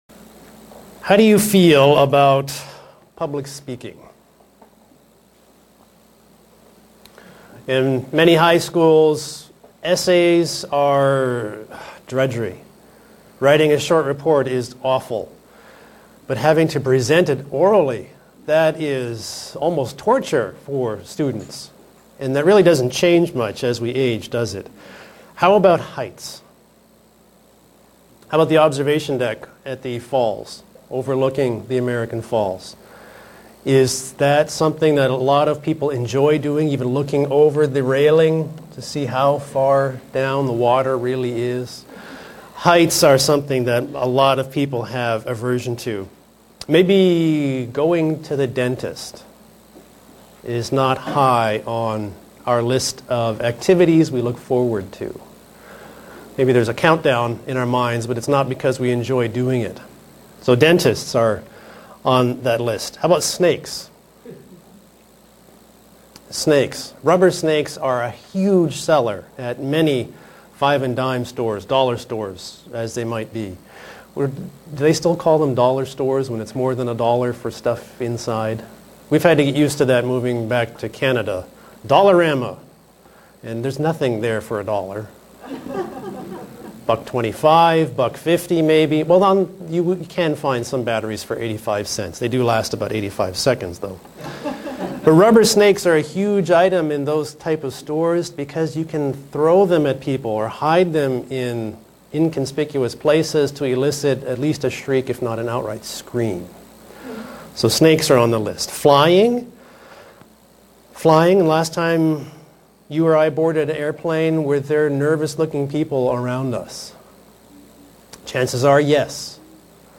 Given in Buffalo, NY Elmira, NY
Print We are to take Him seriously, respect Him and honor Him not be afraid of Him. sermon Studying the bible?